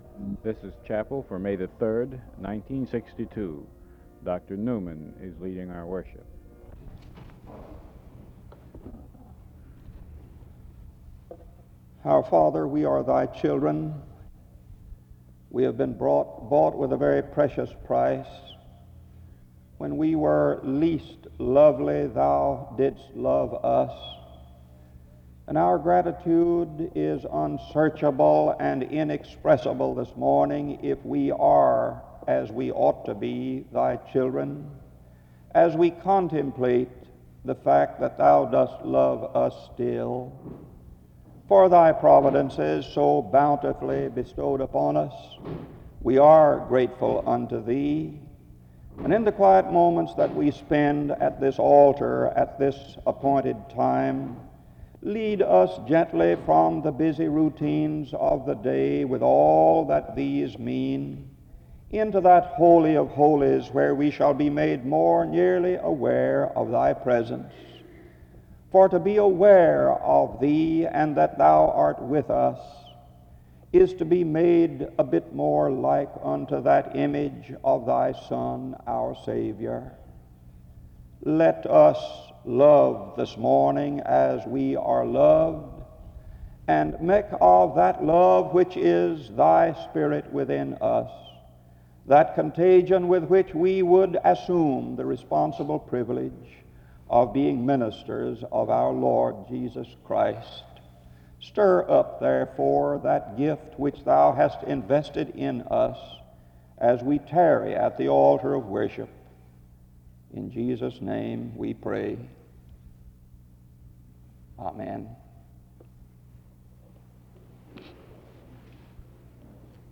A hymn is played from 4:51-7:34.
Another hymn is played from 9:41-13:45.
The service closes in another hymn from 14:01-14:40.
Location Wake Forest (N.C.)